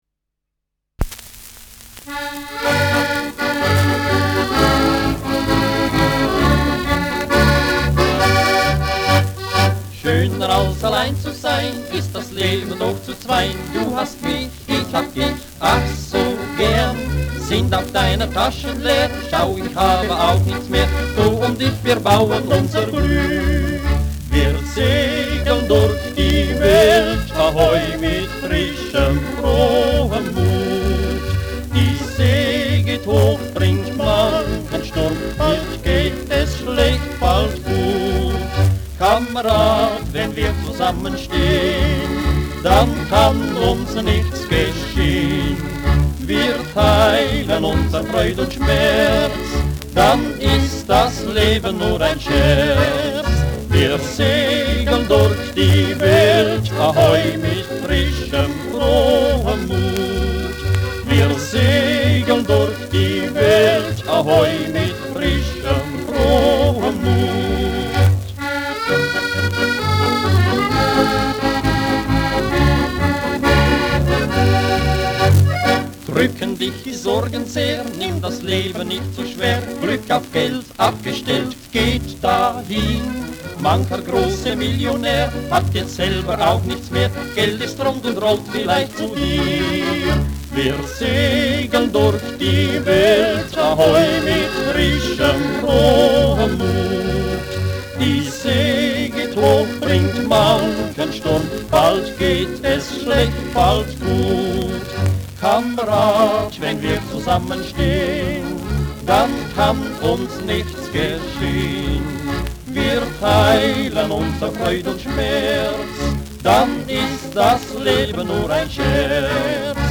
Schellackplatte
leichtes Knistern